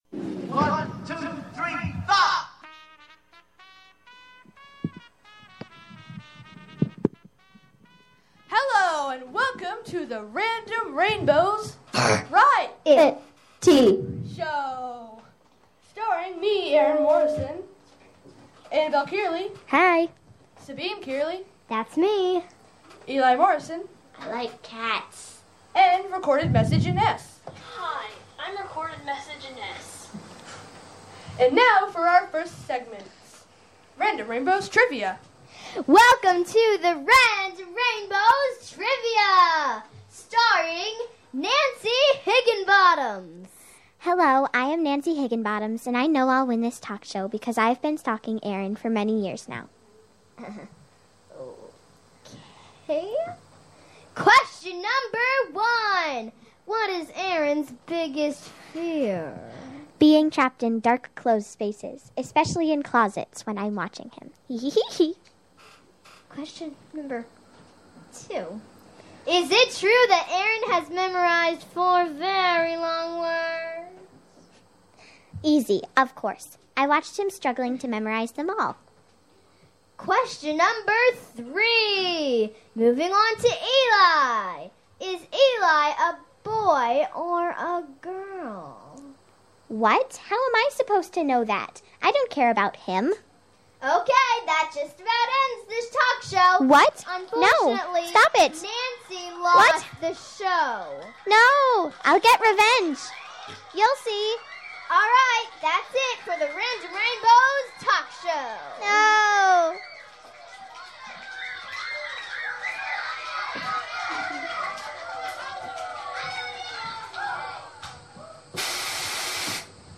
Saturday Afternoon Show: Random Rainbows Musical Comedy Variety : Aug 24, 2013: 4pm - 6pm